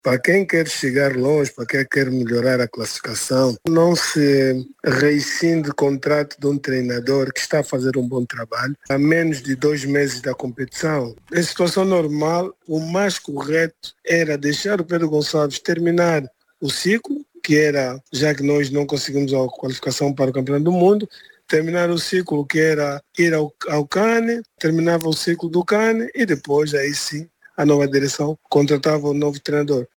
Fabrice Alcebíades Maieco “Akwá”, antigo goleador-mor da selecção nacional, em entrevista à RTP África/Antena 1 de Portugal, diz que, em função dos maus resultados na competição, o técnico francês Patrice Beaumelle pode não ter condições para continuar à frente da selecção nacional.